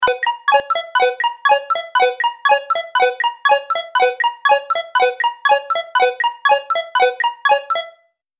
جلوه های صوتی
دانلود صدای ساعت 12 از ساعد نیوز با لینک مستقیم و کیفیت بالا